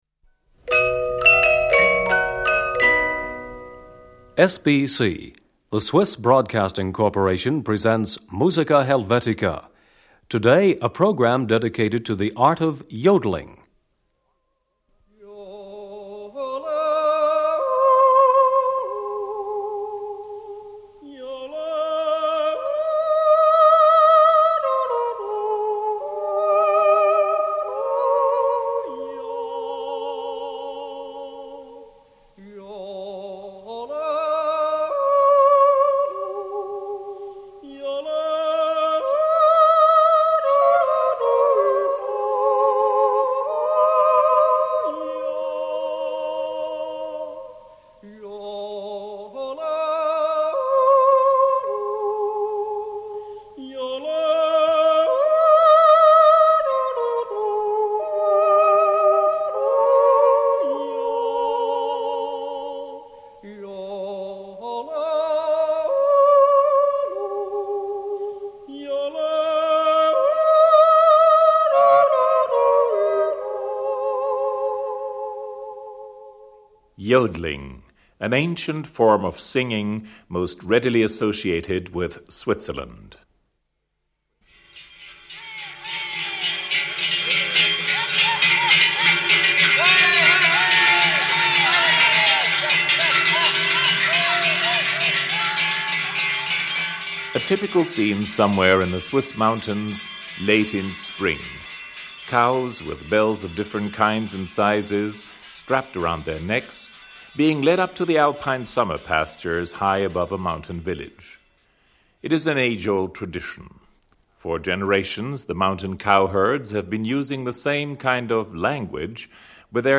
Swiss Folk Music. Yodeling.
Natural yodel performance.